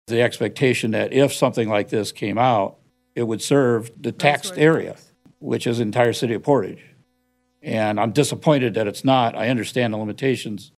Portage city councilman Terry Urban was disappointed to see that it didn’t cover all of Portage, even though everyone in that city pays the Metro Transit millage.